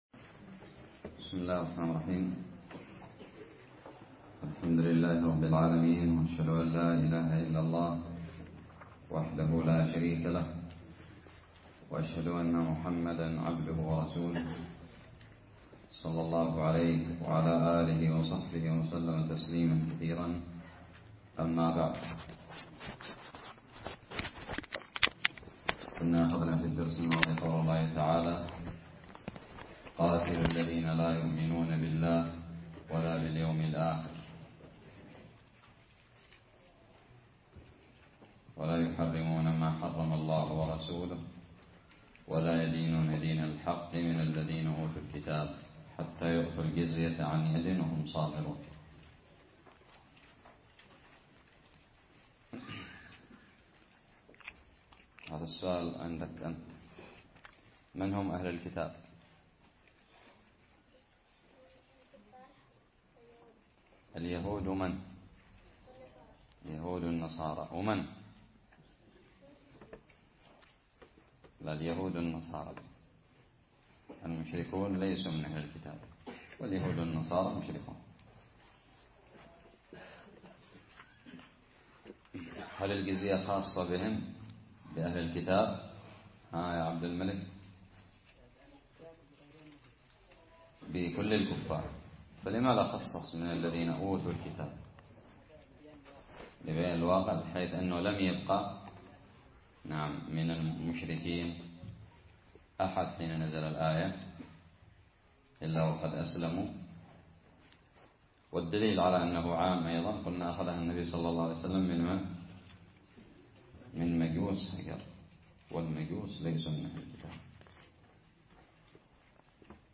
الدرس الرابع عشر من تفسير سورة التوبة
ألقيت بدار الحديث السلفية للعلوم الشرعية بالضالع